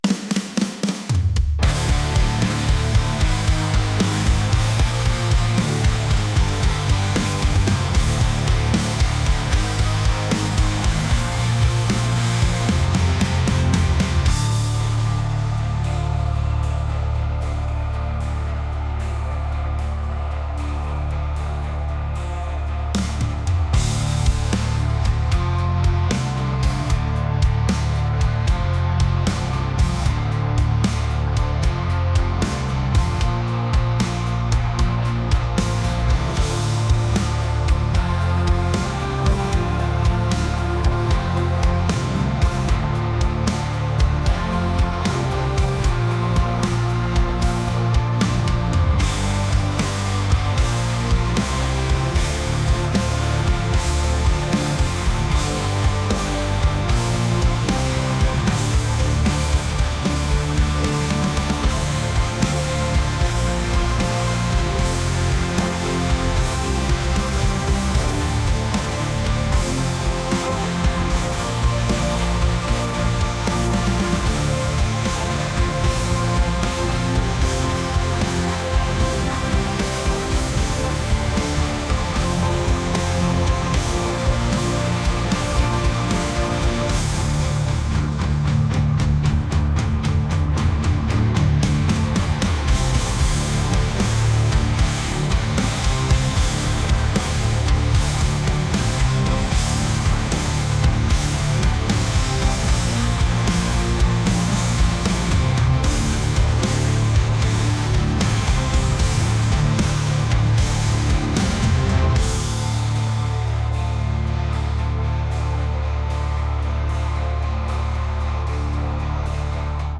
rock | indie | alternative